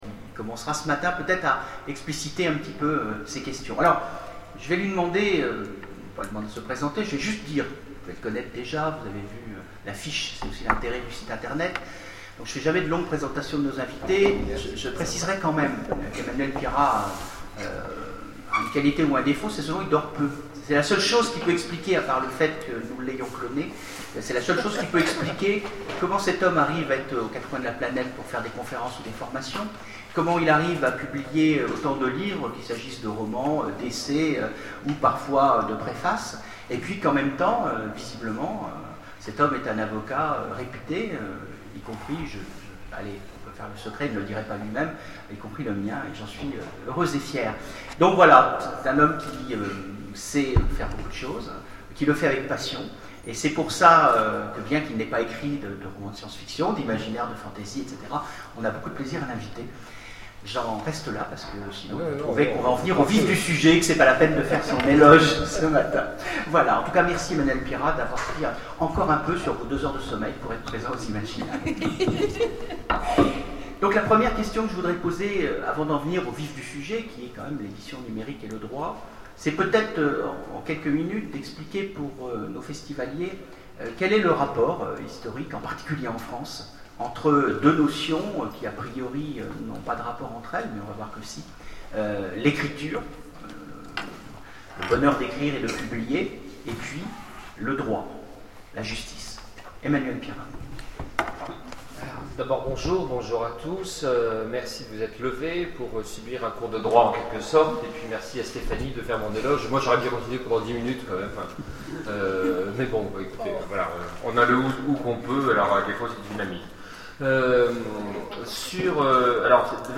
Imaginales 2011 : conférence sur Le livre numérique avec Emmanuel Pierrat
Voici l'enregistrement de la Conférence sur le livre numérique avec Emmanuel Pierrat.